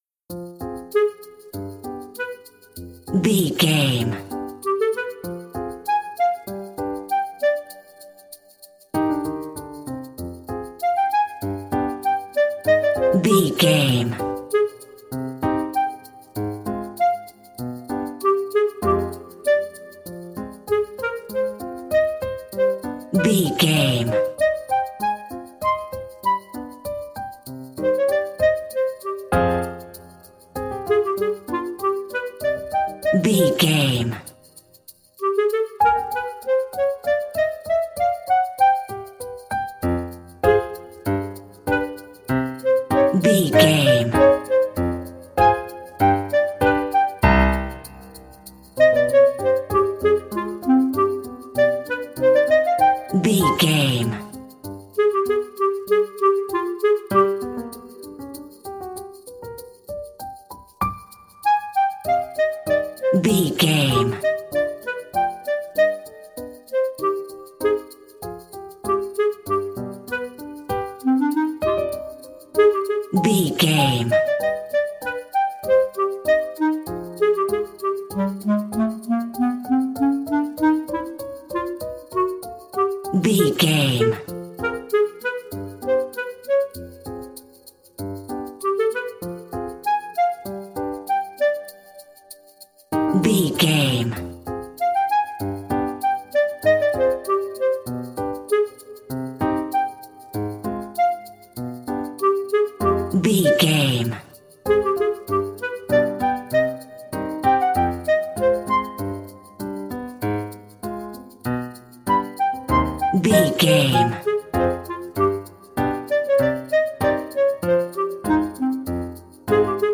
Aeolian/Minor
flute
oboe
strings
orchestra
cello
double bass
percussion
silly
goofy
cheerful
perky
Light hearted
quirky